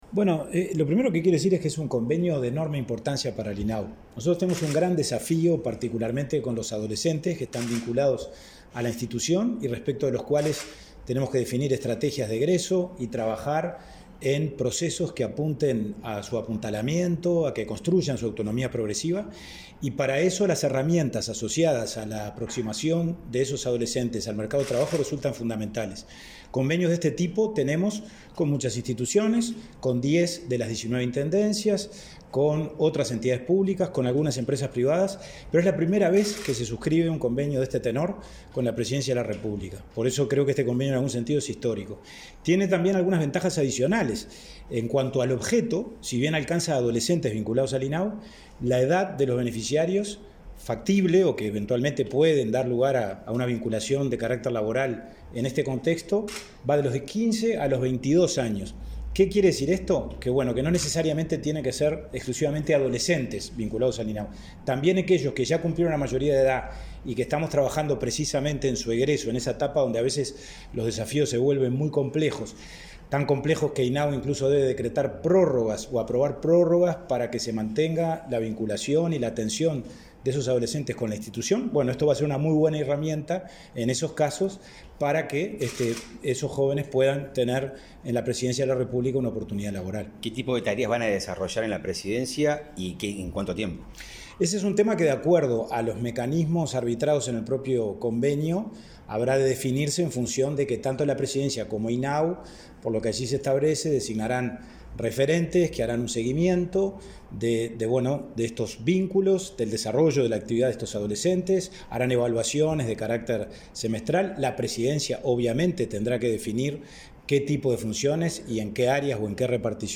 Entrevista al presidente del INAU, Pablo Abdala